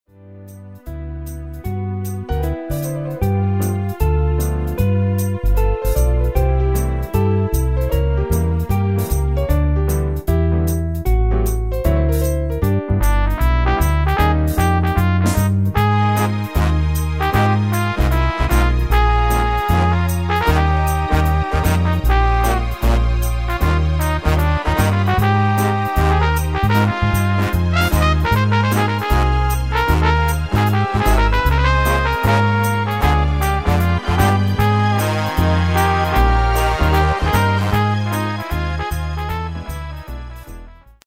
Demo/Koop midifile
Genre: Evergreens & oldies
- Géén vocal harmony tracks
Demo's zijn eigen opnames van onze digitale arrangementen.